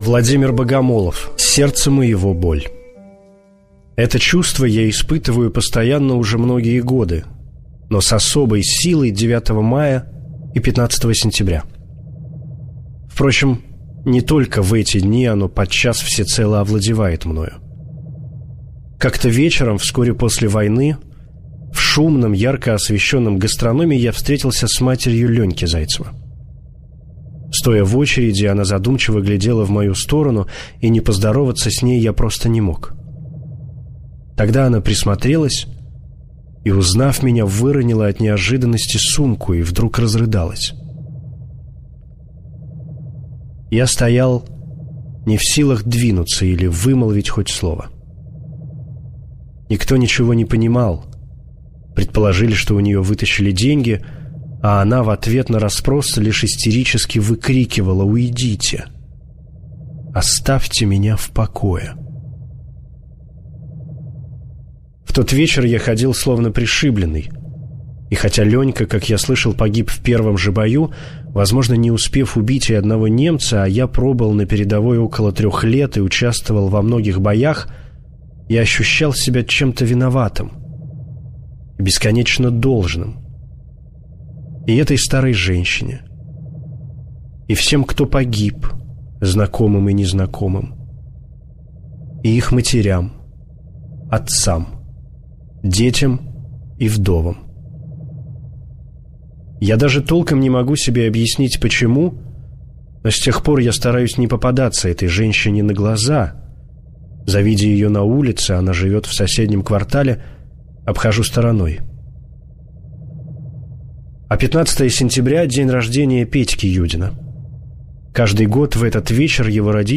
Аудиорассказ «Сердца моего боль»